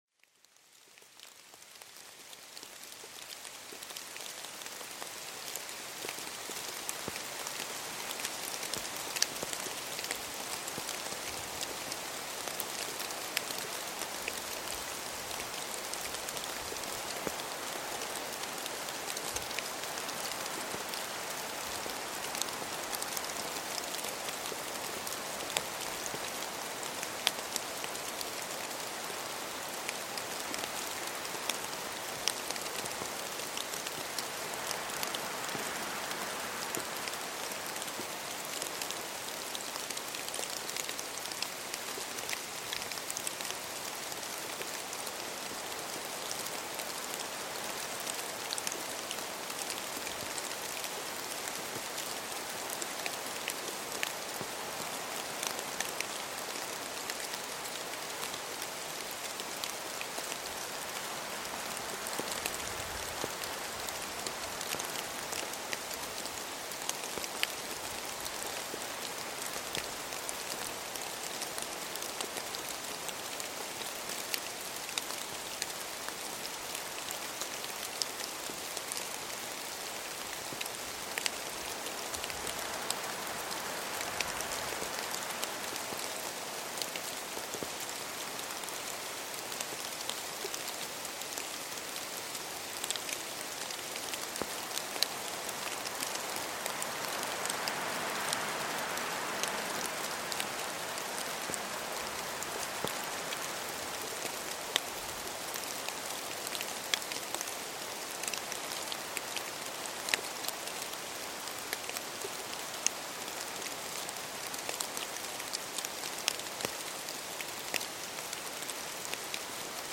ZAUBERFORMEL ENTDECKT: Blättertanz-Magie mit Regen und Wind